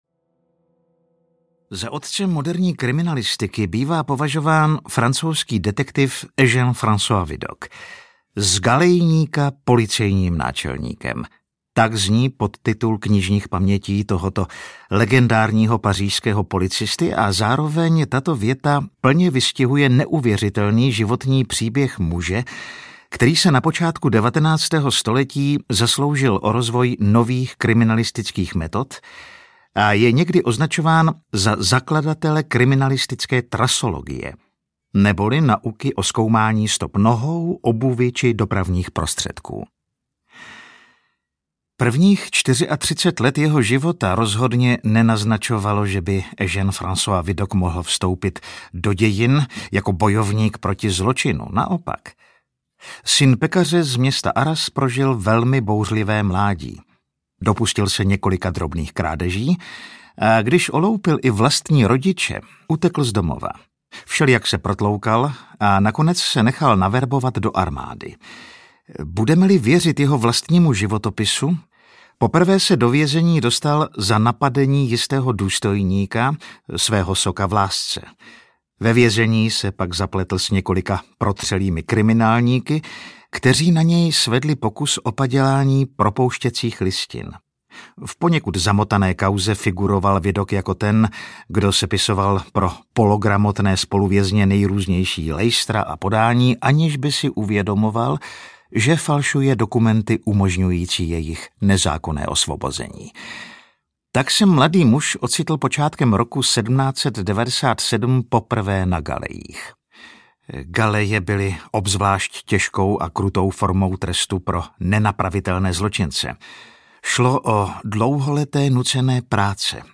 Dobrodružná historie kriminalistiky audiokniha
Ukázka z knihy
• InterpretAleš Procházka